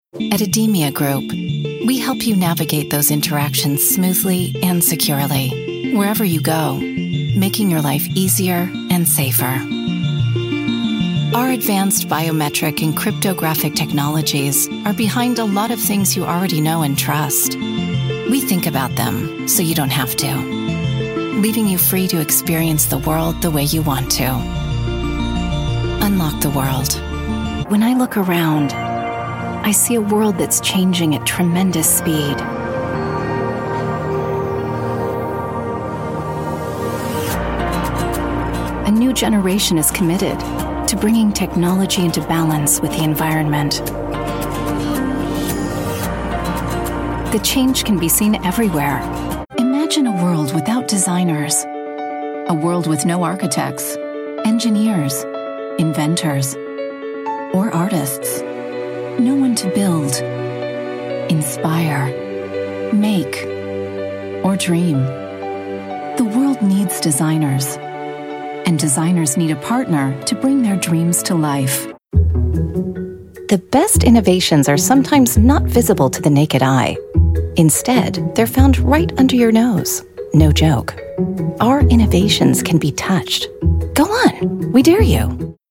Corporate Demo